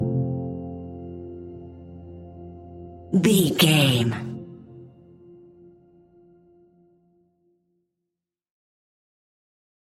Ionian/Major
chilled
laid back
Lounge
sparse
new age
chilled electronica
ambient
atmospheric
morphing
instrumentals